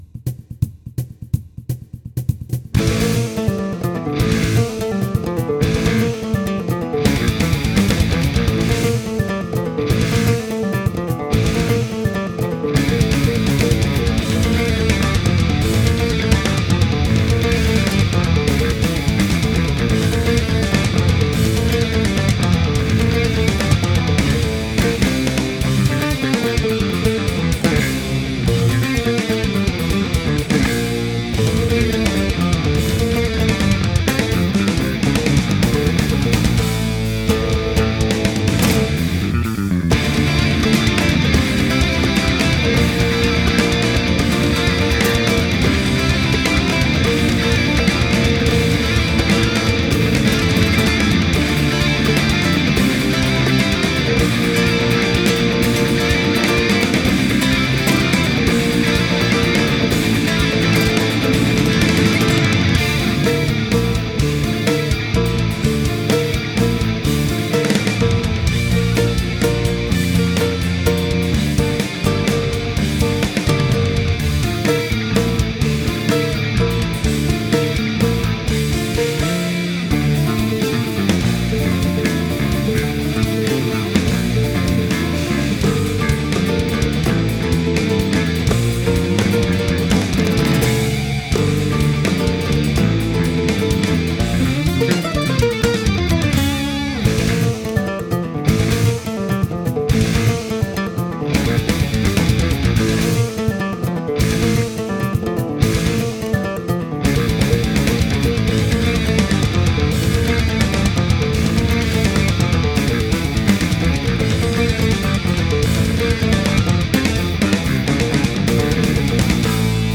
Genre: Guitar Virtuoso, Speed Metal, Flamenco